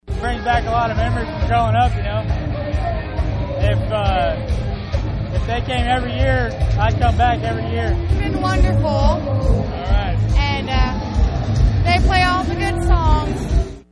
The musical group Hairball not only sounded like the best rock bands of all time, they dressed like them too before a great crowd Friday night at the Finney County Fairgrounds.
hairball-concert-two.mp3